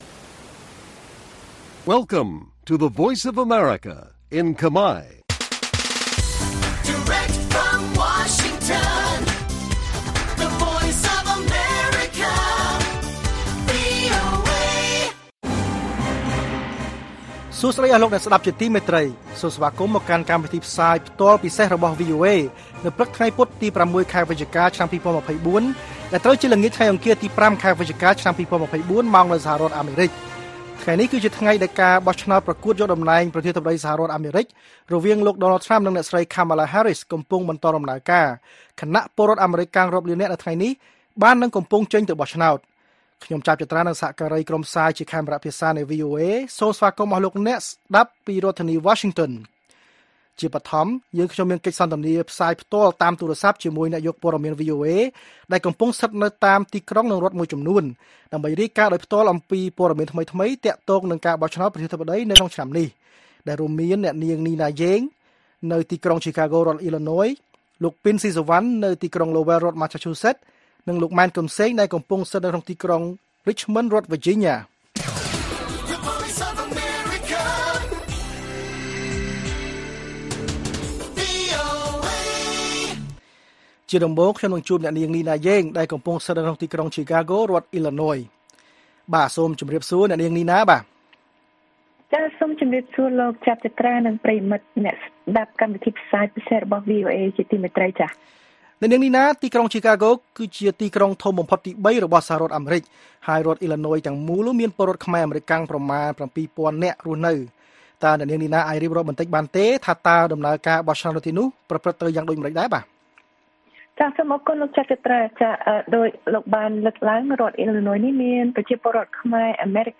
ព័ត៌មាននៅថ្ងៃនេះមានដូចជា កិច្ចសន្ទនាផ្ទាល់ជាមួយអ្នកយកព័ត៌មានវីអូអេស្តីពីការបោះឆ្នោតប្រធានាធិបតីអាមេរិកឆ្នាំ ២០២៤។ ភាពខុសគ្នារវាងសំឡេងឆ្នោតរបស់អង្គបោះឆ្នោត និងសំឡេងឆ្នោតសរុបនៅសហរដ្ឋអាមេរិក និងព័ត៌មានផ្សេងទៀត។